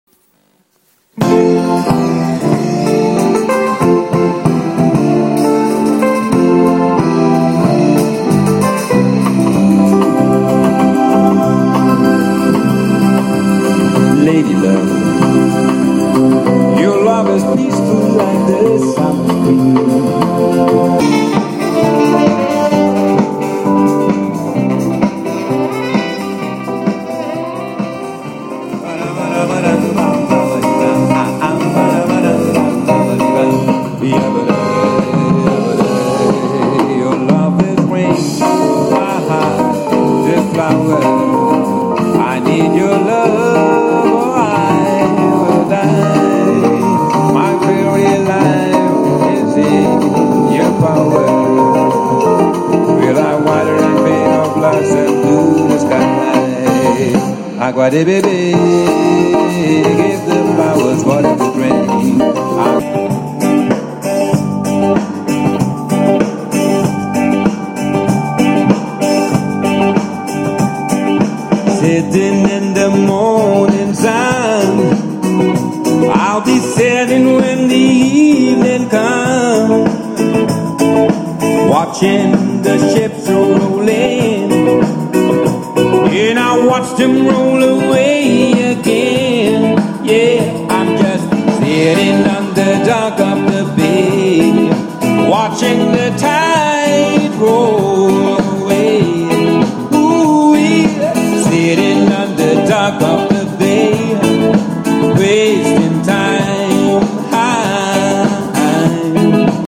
Life mobile recording II